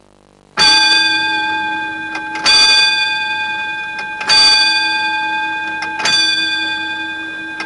Dinnertime Sound Effect
Download a high-quality dinnertime sound effect.